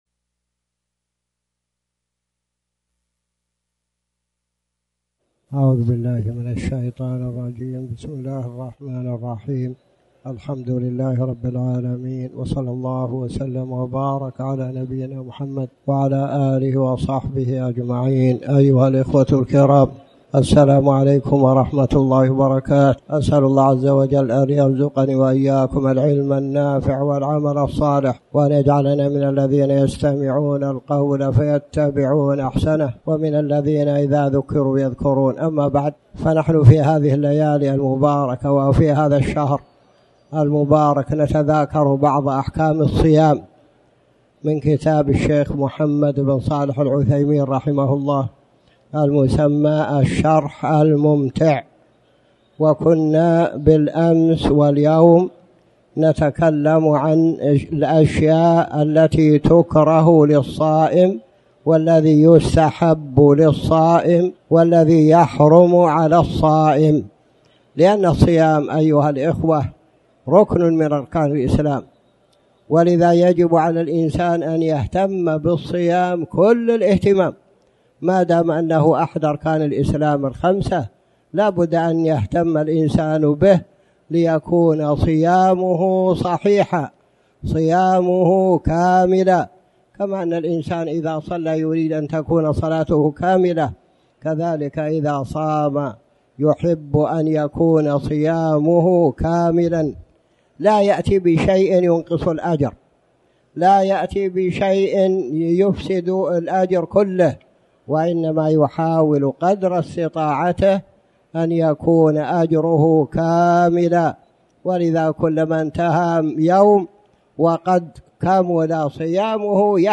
تاريخ النشر ٦ شعبان ١٤٣٩ هـ المكان: المسجد الحرام الشيخ